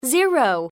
KeyTone_0.mp3